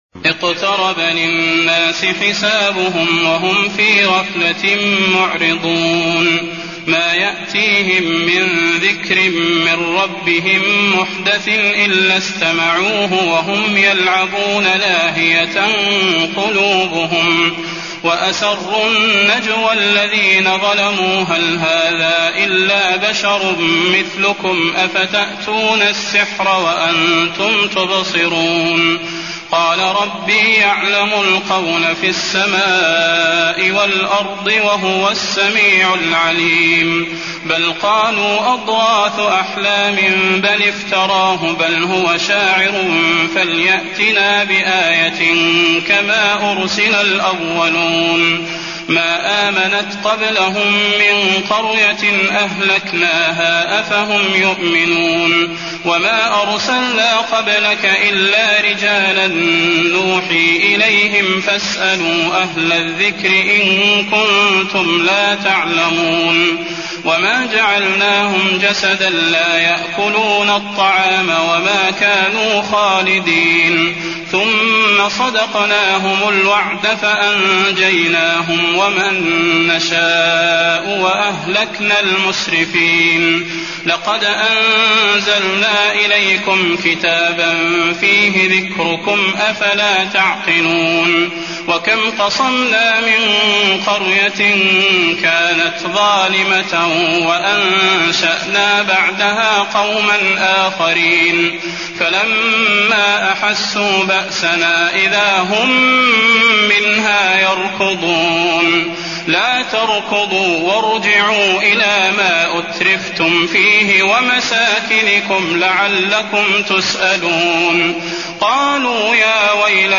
المكان: المسجد النبوي الأنبياء